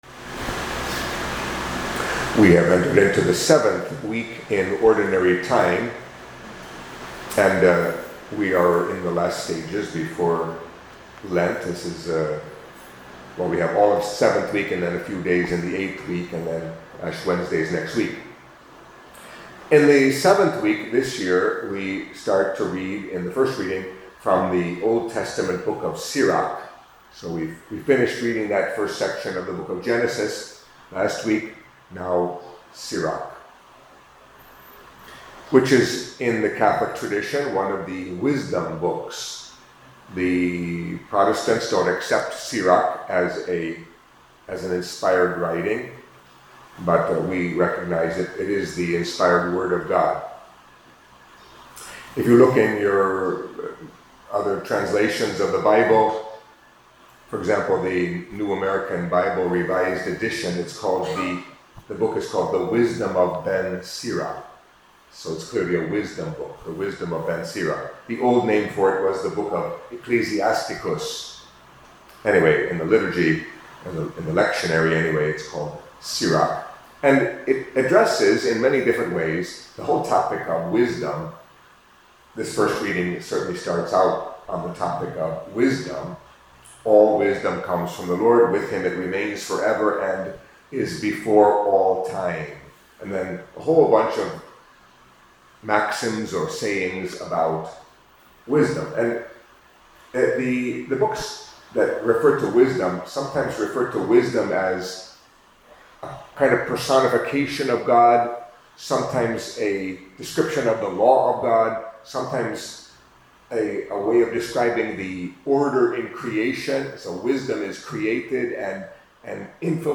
Catholic Mass homily for Monday of the Seventh Week in Ordinary Time